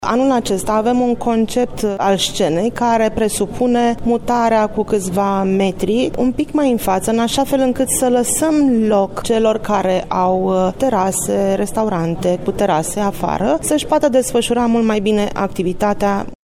Doina Gradea, președinte director general al TVR: